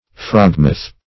Frogmouth \Frog"mouth`\, n. (Zool.)